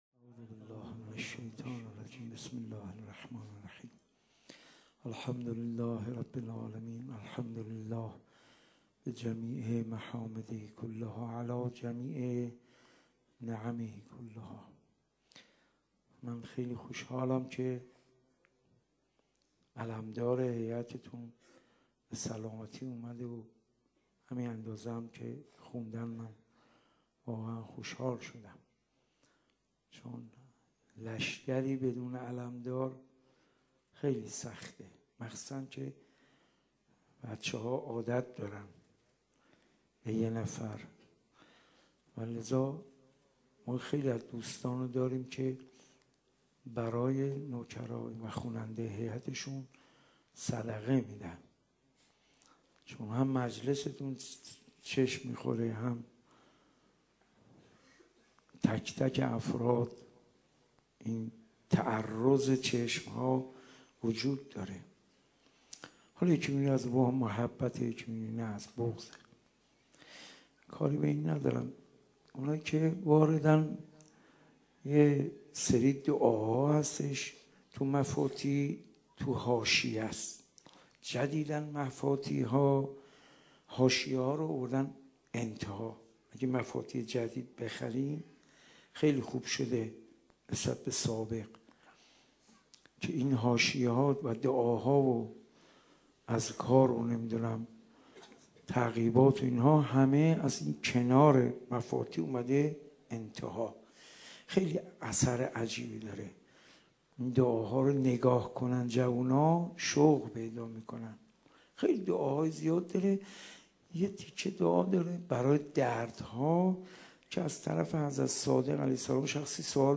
1390صحبت و سخنرانی درخصوص حضرت امیر علیه اسلام